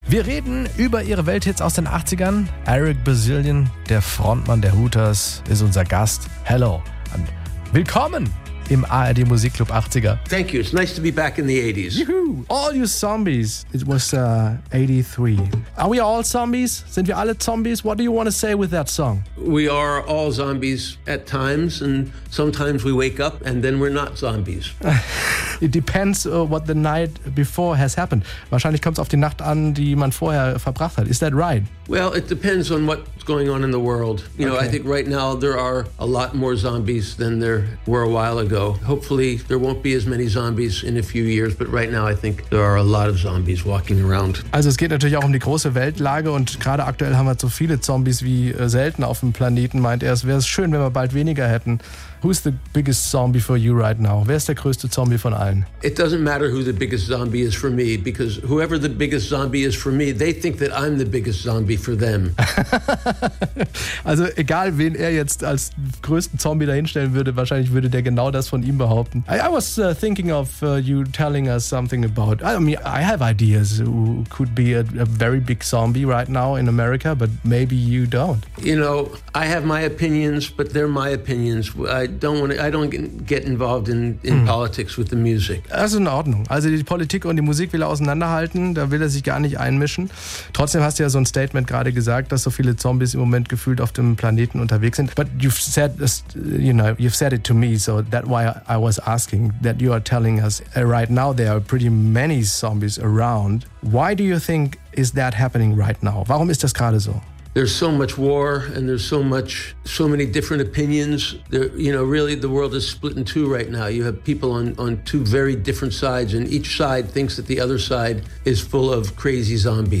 Und auch wenn der Song in den 80ern rausgekommen ist, wandeln für Eric Bazilian auch jetzt gerade viele Zombies auf der Welt umher, erzählt er im SWR1 Interview.